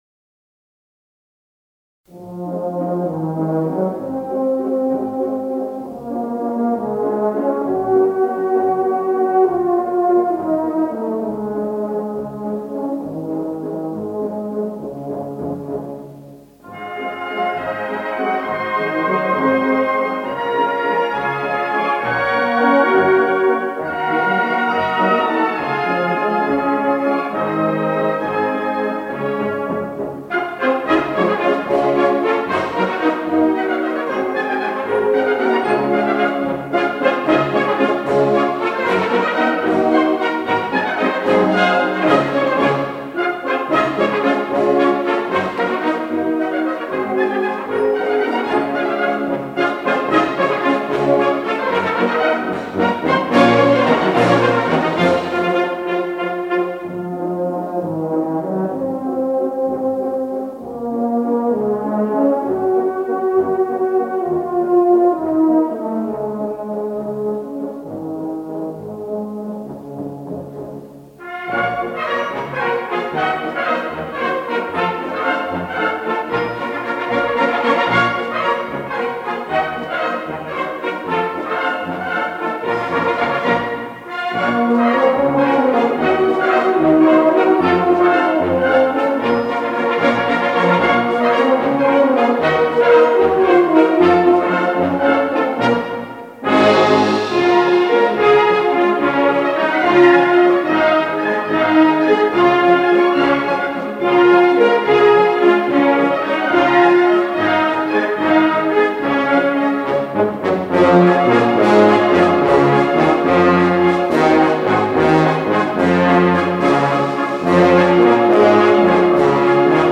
Предлагаю небольшой вечерний концерт старых вальсов в исполнении духовых оркестров.